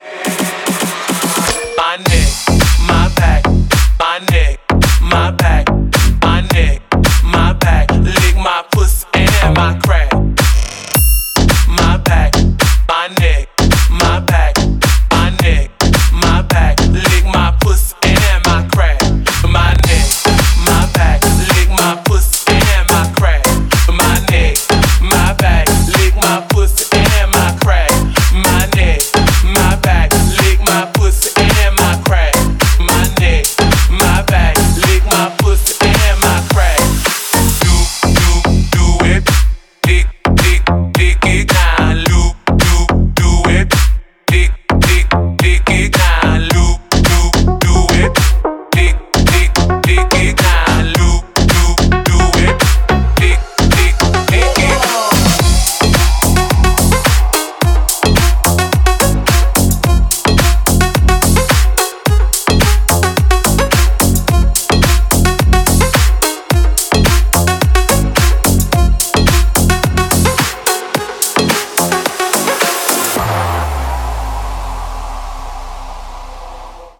• Качество: 128, Stereo
мужской голос
громкие
качающие
Стиль: G-house, bass house